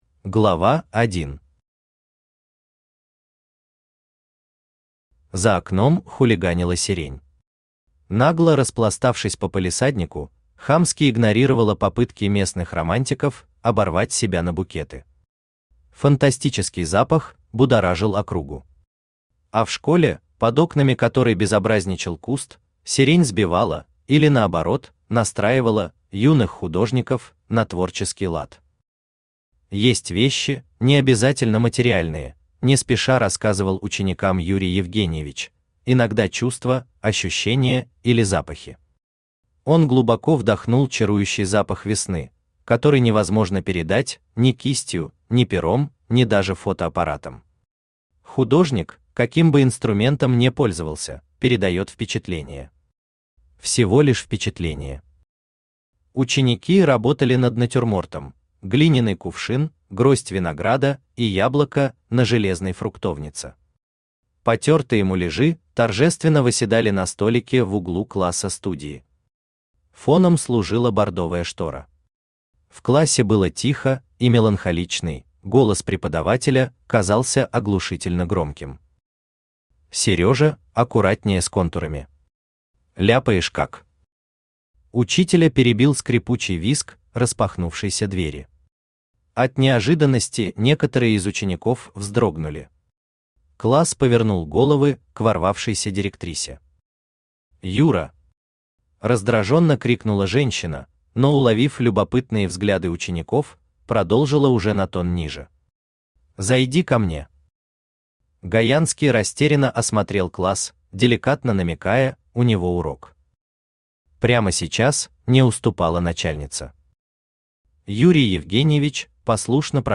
Аудиокнига ПЕС | Библиотека аудиокниг
Aудиокнига ПЕС Автор ШаМаШ БраМиН Читает аудиокнигу Авточтец ЛитРес.